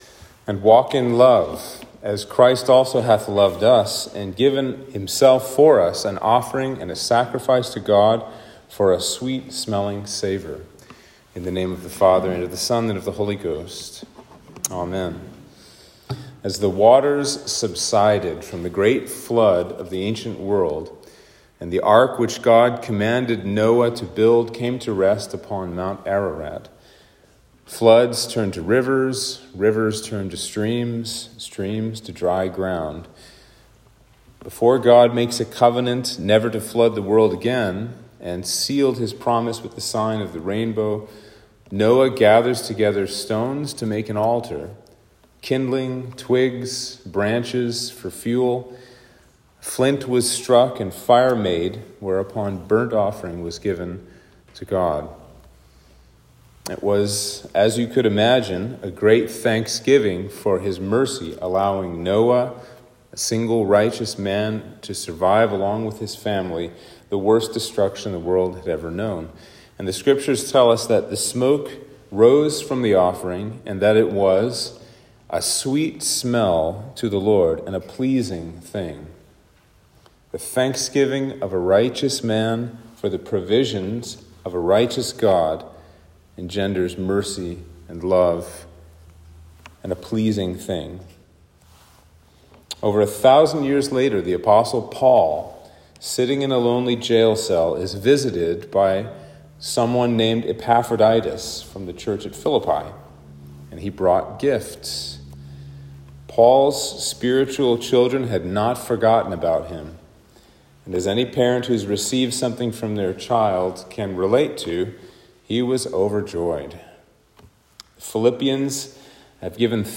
Sermon for Lent 3